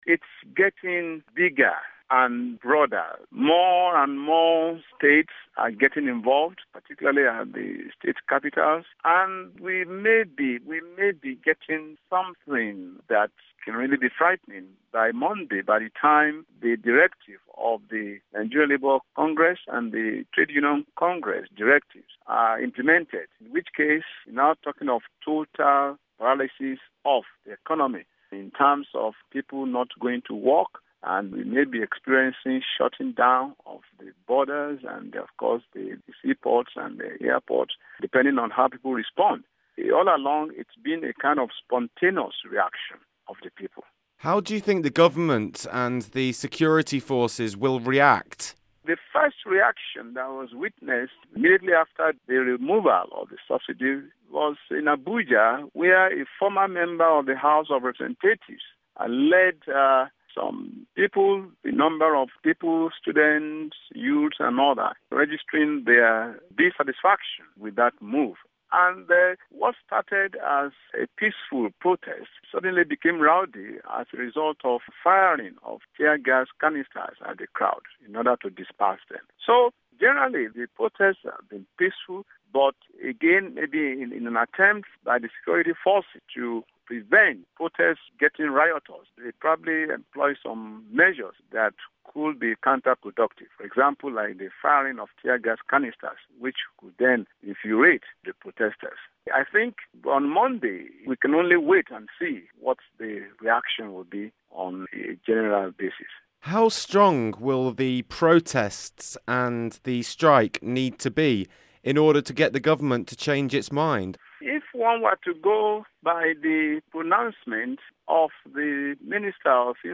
Interview: Former Lagos East Senator Adeleke Olurunnimbe Mamora, Action Congress of Nigeria party
interview-occupynigeria-fuel-protests-q-a-former-lagos-east-senator-mamora-action-congress-party.mp3